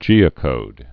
(jēə-kōd)